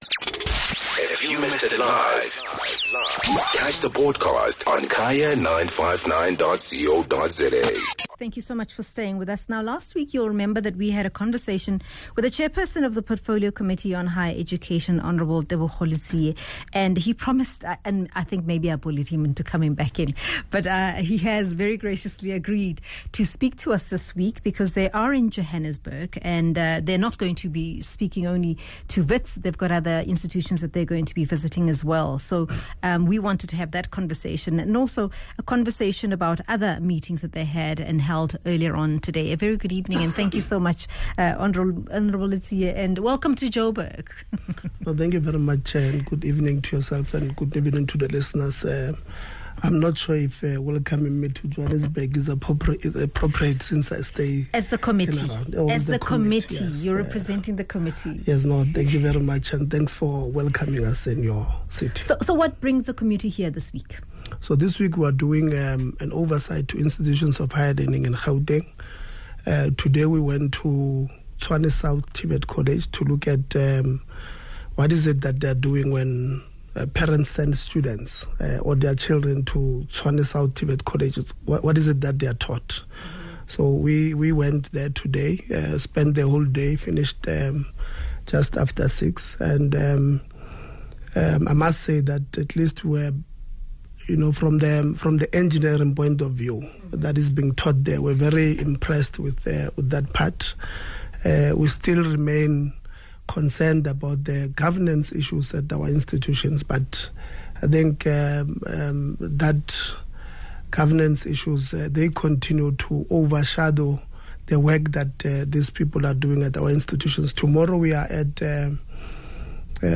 24 Feb DISCUSSION: State of SA’s Higher Education system